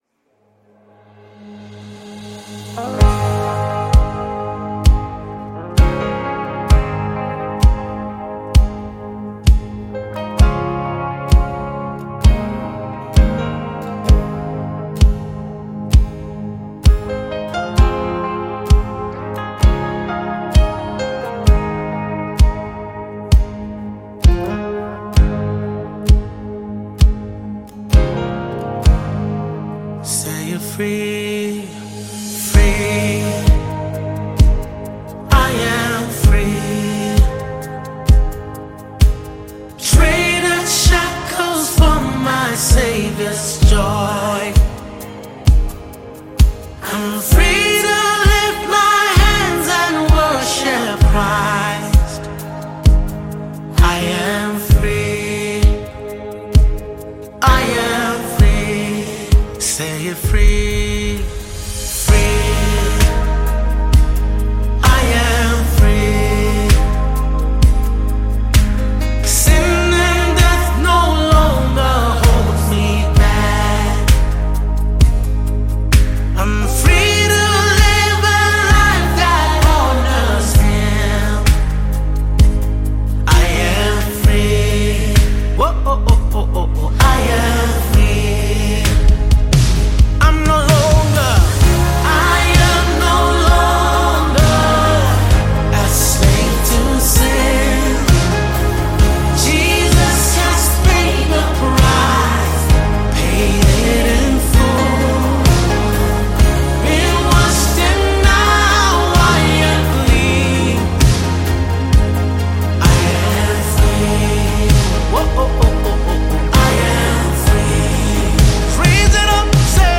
Mp3 Gospel Songs